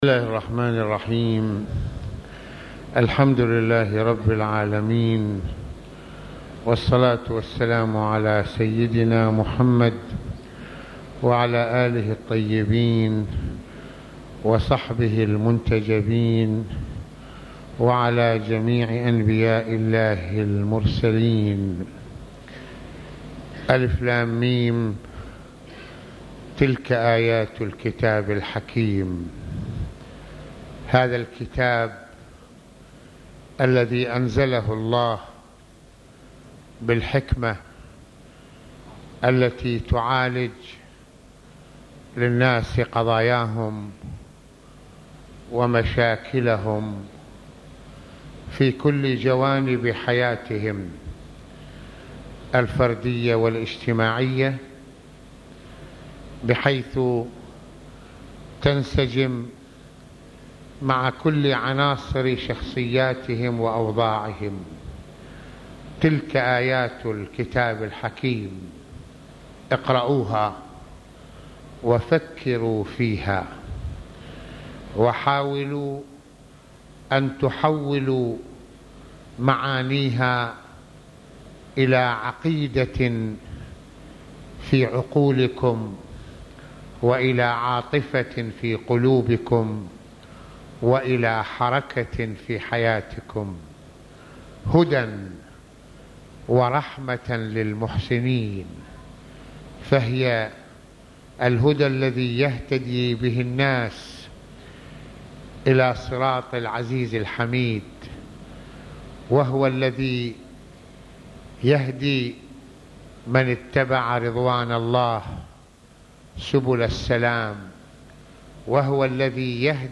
- يتحدّث العلامة المرجع السيّد محمّد حسين فضل الله(رض) في هذه المحاضرة عن كتاب الله عز وجل لجهة الحكمة التي تعالج مشاكل الإنسان والحياة بكلّ دقة ومسؤولية، فالكتاب هو الهداية والرحمة التي تشمل كل واقع الإنسان الفكري والشعوري..